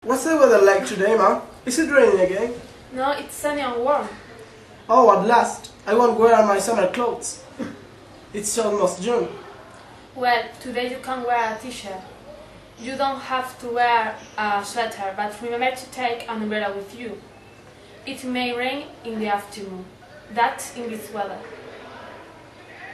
Everyday conversations
Chico sentado y chica de pié junto a mueble con libros mantienen un conversación.